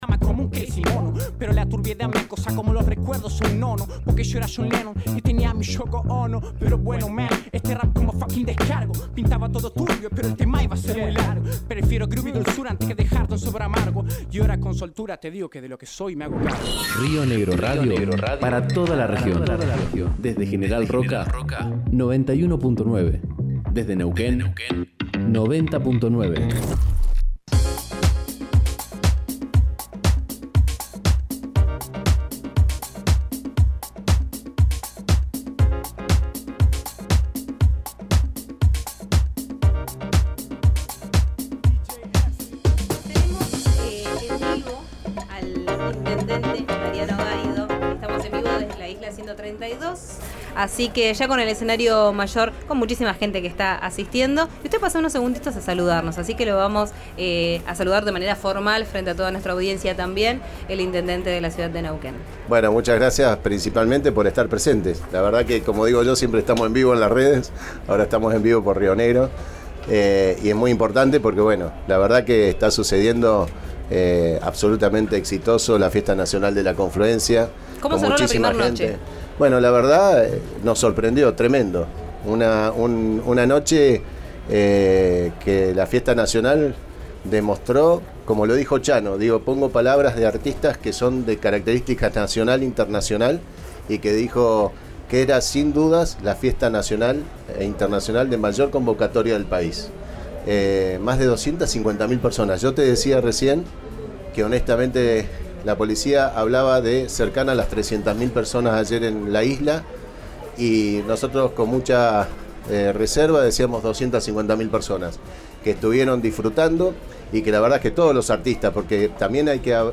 Gaido fue entrevistado en vivo en el stand que RÍO NEGRO RADIO tiene en el predio de la Fiesta de la Confluencia.